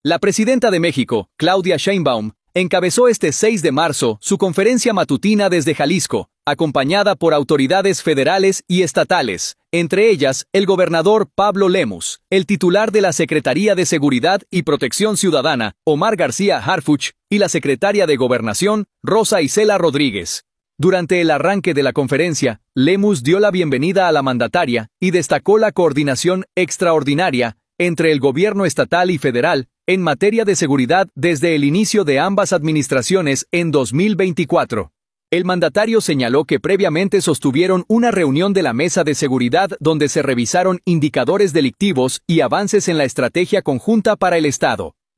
Durante el arranque de la conferencia, Lemus dio la bienvenida a la mandataria y destacó la coordinación “extraordinaria” entre el gobierno estatal y federal en materia de seguridad desde el inicio de ambas administraciones en 2024. El mandatario señaló que previamente sostuvieron una reunión de la mesa de seguridad donde se revisaron indicadores delictivos y avances en la estrategia conjunta para el estado.